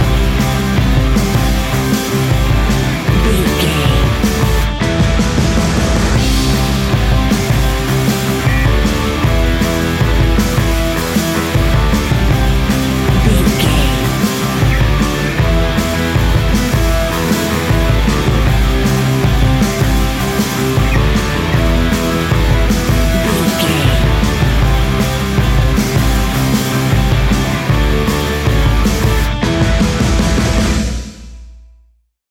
Ionian/Major
A♭
hard rock
heavy rock
distortion